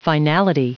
Prononciation du mot finality en anglais (fichier audio)
Prononciation du mot : finality